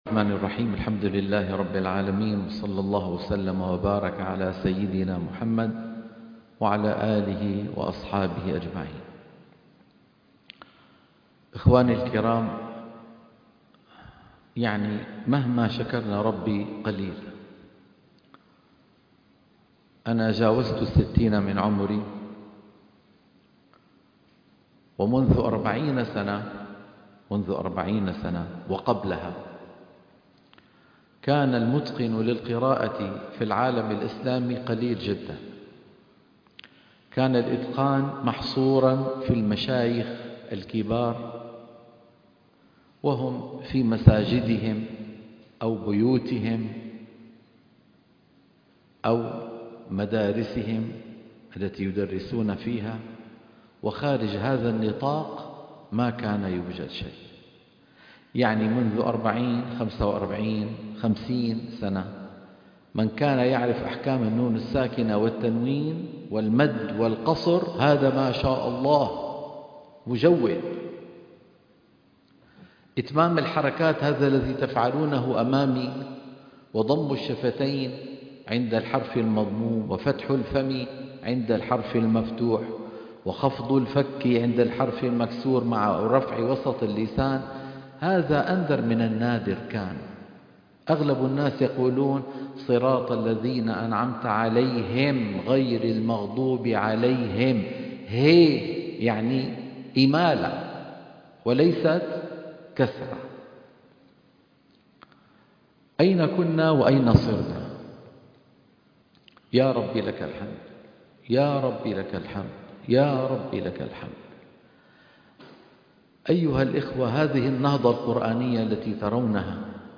وصايا لحفاظ القرآن الكريم في ختام المسابقة الدولية